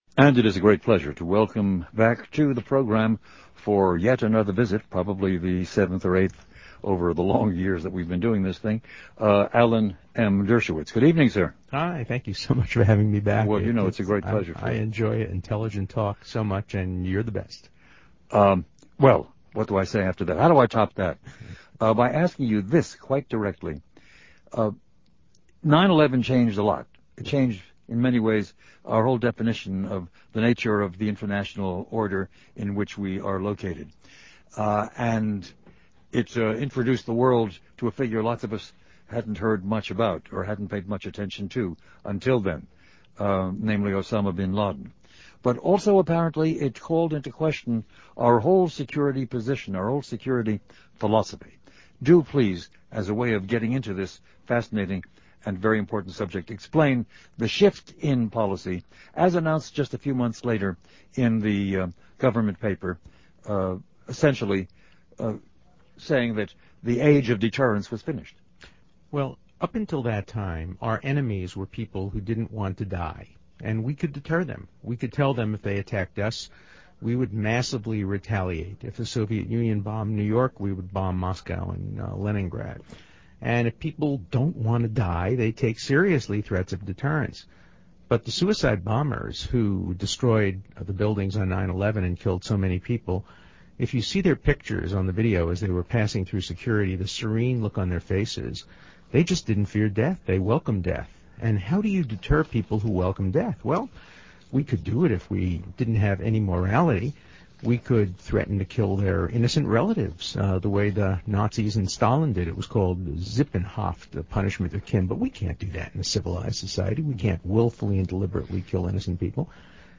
Milt Rosenberg is a daily podcast that features provocative and thought provoking discussion centered on the world of ideas.
A Conversation With Alan Dershowitz